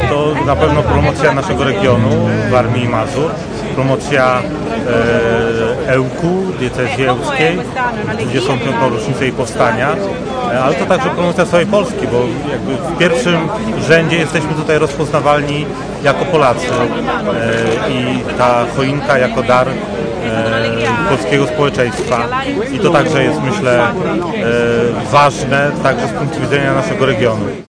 To również wspaniała promocja województwa i całego naszego regionu mówi Artur Chojecki – wojewoda Warmińsko Mazurski.